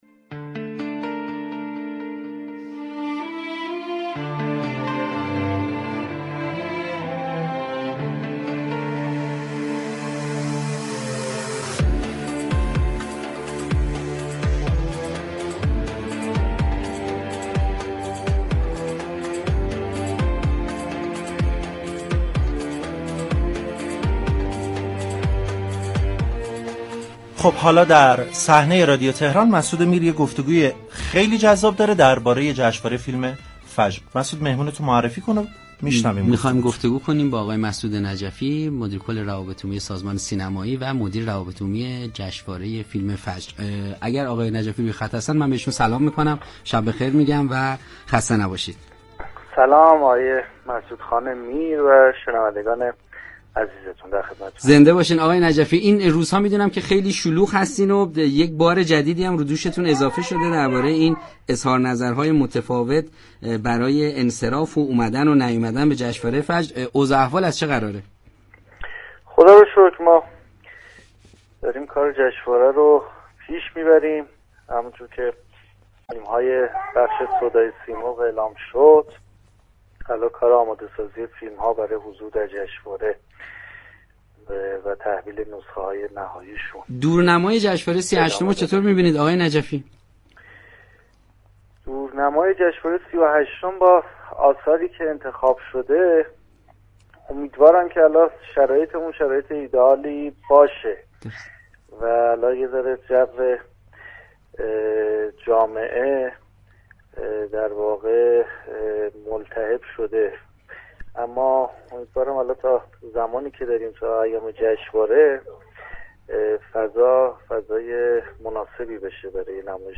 گفت‌و‌گو با برنامه‌ی صحنه